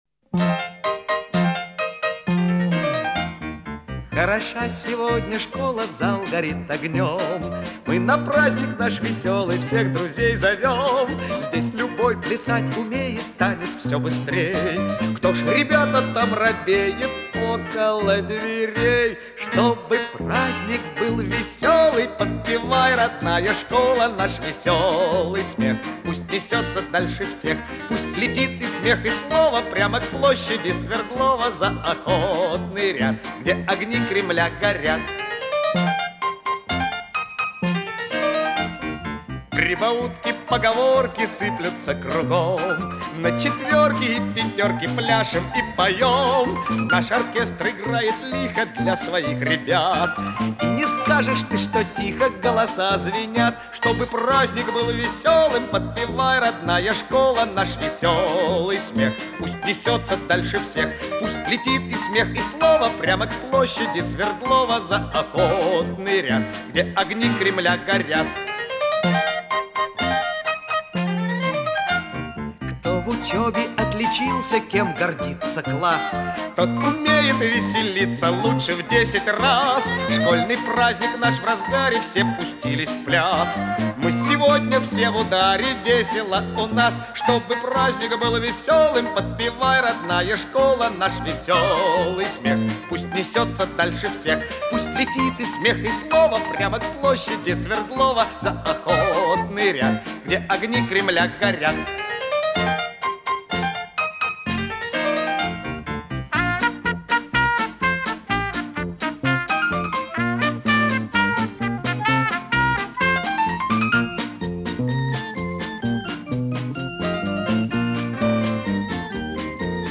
Радостная песня счастливых советских детей.